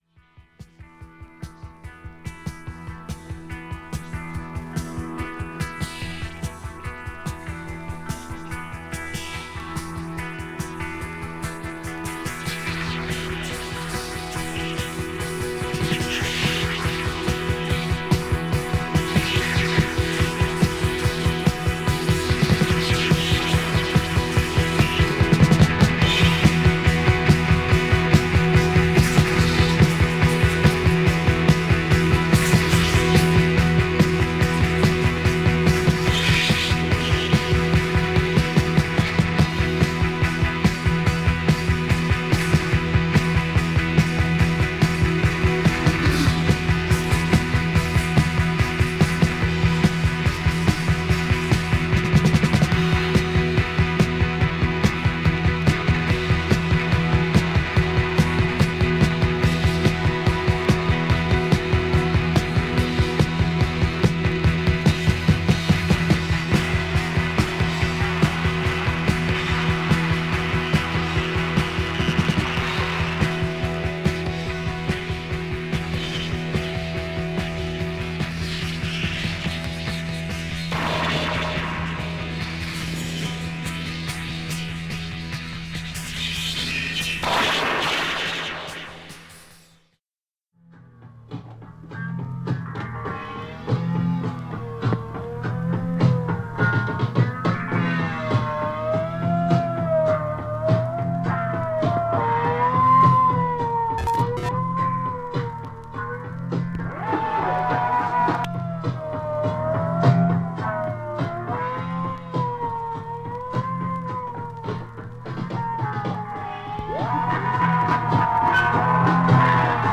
KRAUTROCK